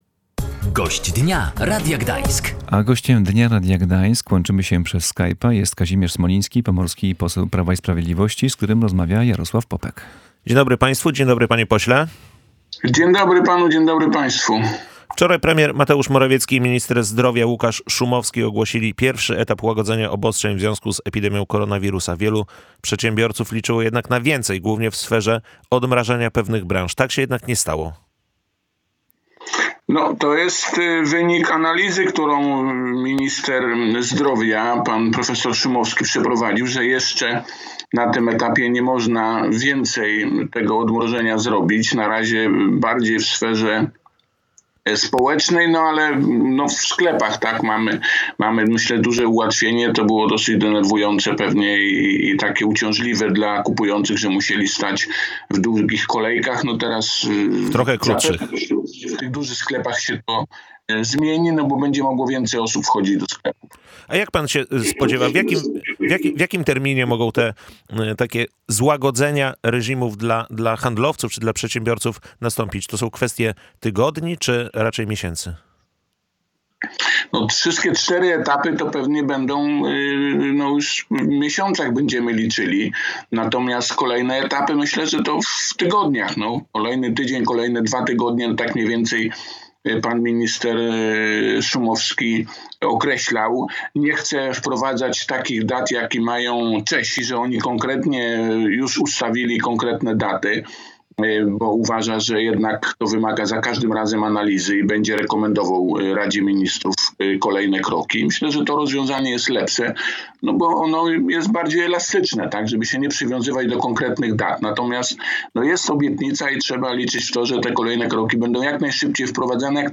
odpowiadał Gość Dnia Radia Gdańsk – Kazimierz Smoliński, pomorski poseł Prawa i Sprawiedliwości.